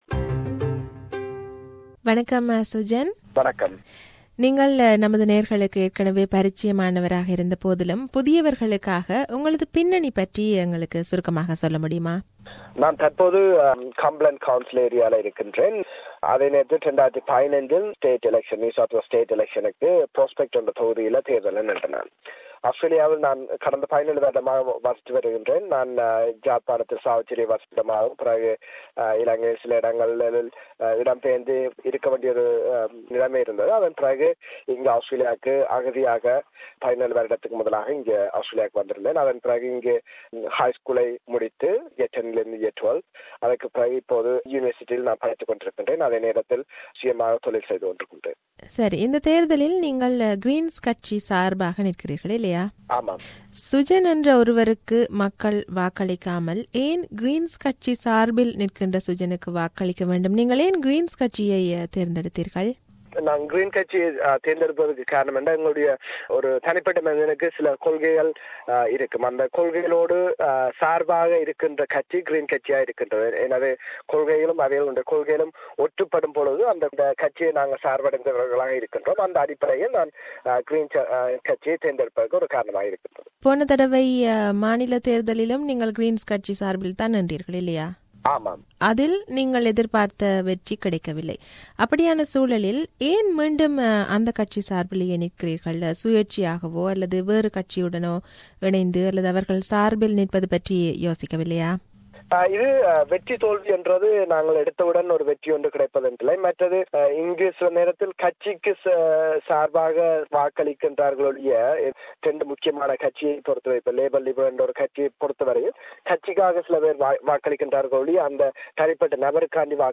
NSW Local Council Elections 2017: Interview